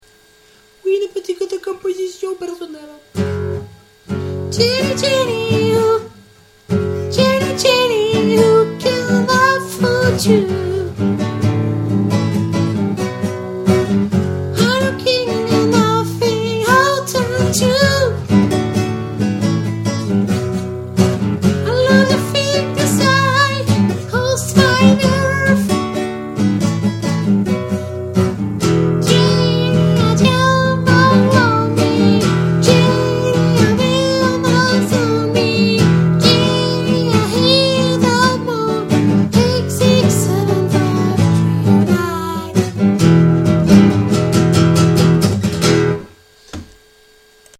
Un crescendo qui se termine sur une bonne saturation vocale quoi demander de mieux Twisted Evil